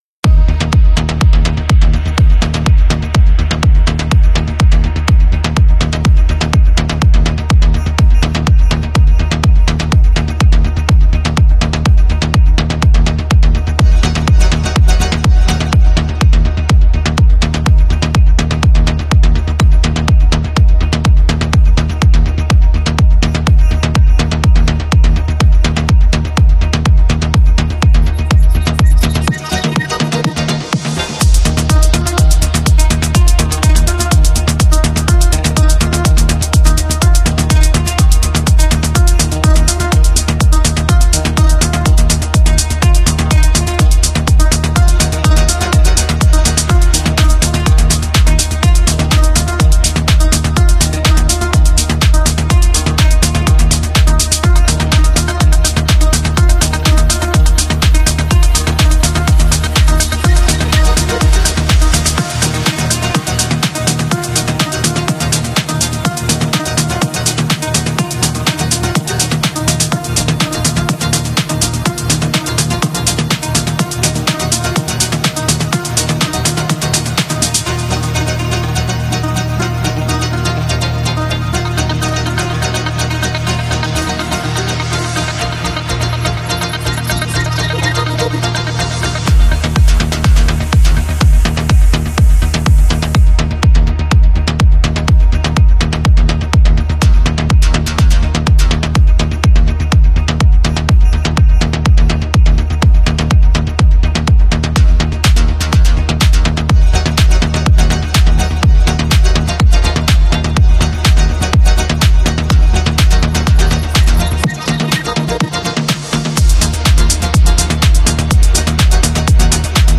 Genre: Progressive House